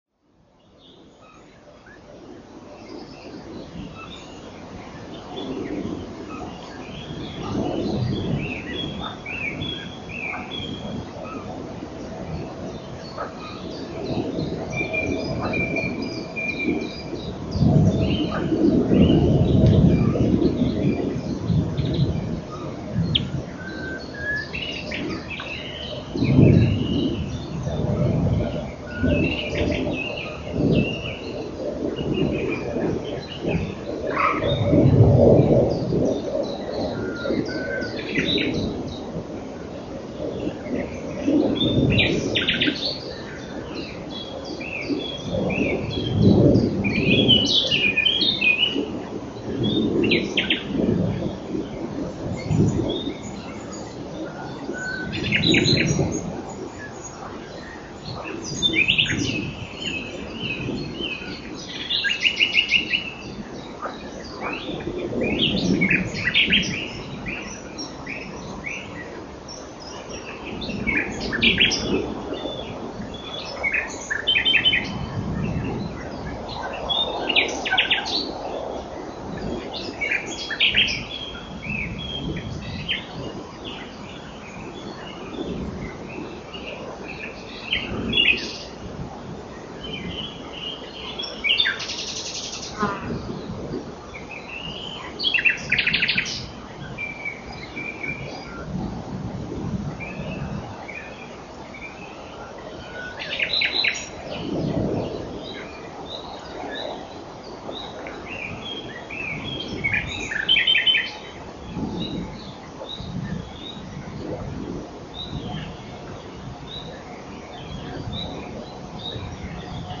als krönchen gibt es noch 5min  zum nachhören, diesmal auch in besserer qualität, weil mit besserem mikrofon aufgenommen.
leider sind nicht so viele vogelstimmen zu hören, was wohl auch an der uhrzeit (nachmittag) und temperatur lag. allerdings ist ein bläßhuhn revierstreit dabei, inklusive über-wasser-flatter-laufen.
5min an einem der Karower Teiche
ja, der sound ist interessant. wenn du dir die frequenzkurve am rechner ansiehst, dann fällt auch besonders der grosse ausschlag vom vorbeifliegenden jet auf (das mächtige brummen). daran hat man sich irgendwo gewöhnt, aber sobald du bewusst drauf achtest ist es viel lauter als gedacht.
ich war ein wenig enttäuscht, denn die b-hühner sind richtig von links nach rechts über den teich gefegt, das kommt nur leider bei der aufnahme nicht raus. auf dem mikro steht zwar stereo drauf, ist aber nicht drin. da muß ich mich mal kundig machen, vielleicht finde ich ja ein besseres auf ebay (dieses ist eh nur geliehen). aber frag bitte nicht “wofür eigentlich?”